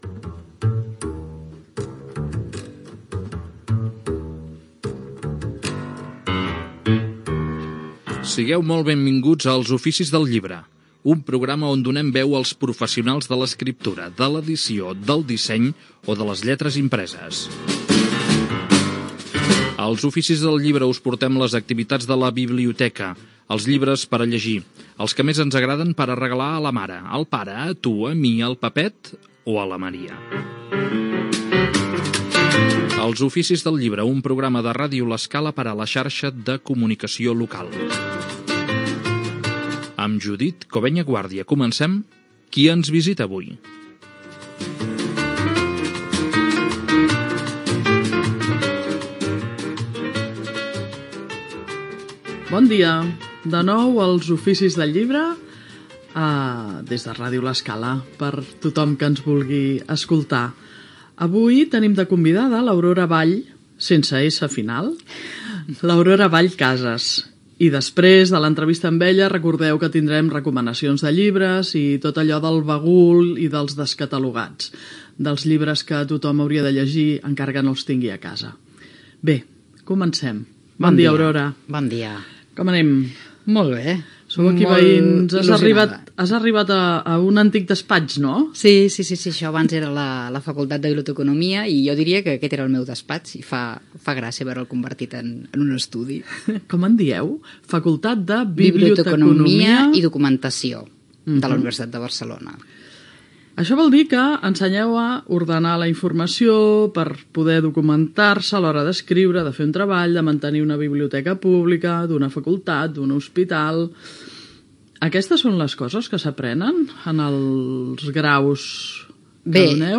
Careta del programa, entrevista
Cultura